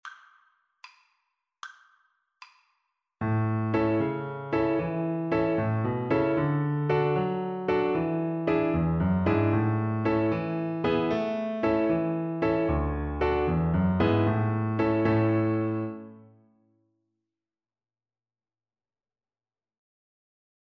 A major (Sounding Pitch) (View more A major Music for Violin )
6/8 (View more 6/8 Music)
Joyfully .=c.76